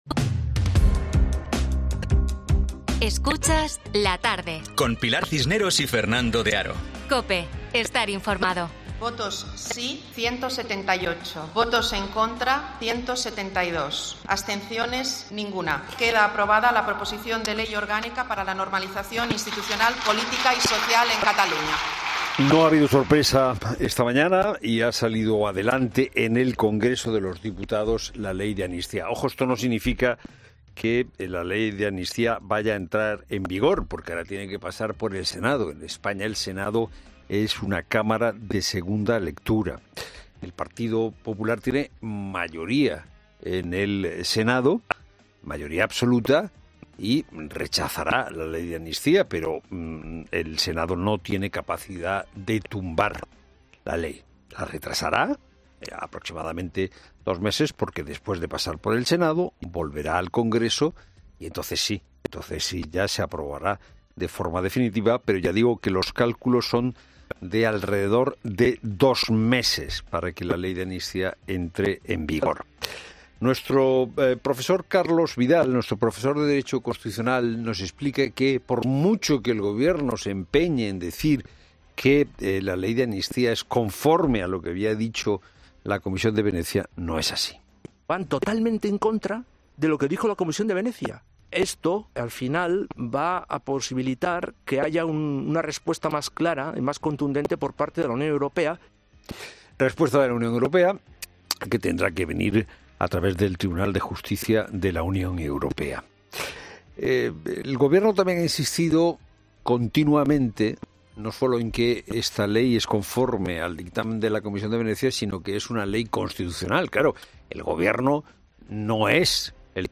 En este día tan importante también se ha pasado por los micrófonos de 'La Tarde de COPE' para hablar de esta norma.